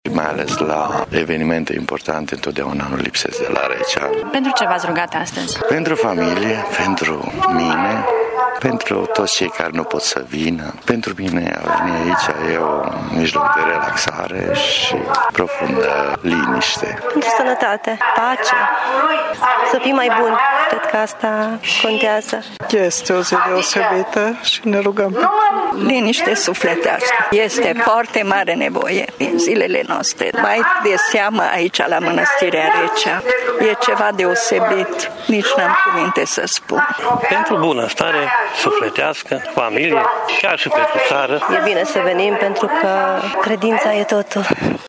Mănăstirea Recea este, de asemenea, închinată Maicii Domnului (Nașterea Sfintei Fecioare Maria) prin urmare, întregul complex monastic a devenit neîncăpător, astăzi, de cinstitorii Mariei.